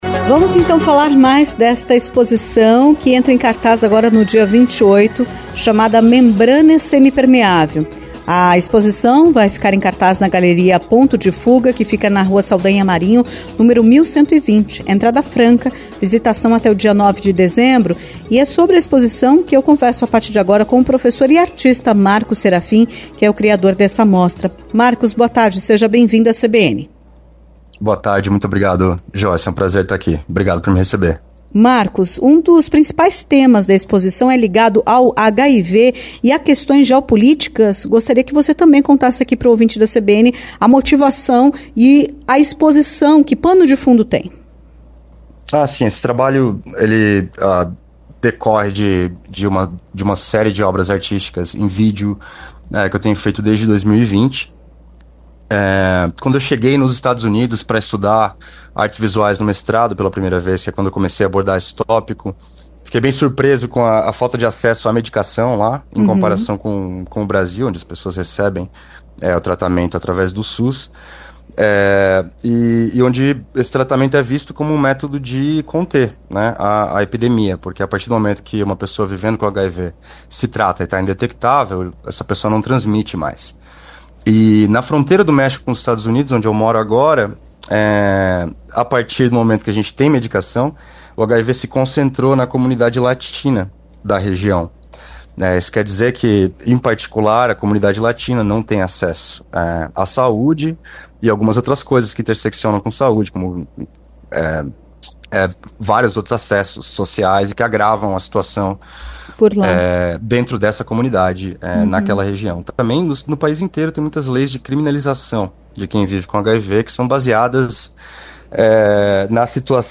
Entrevista-26-11.mp3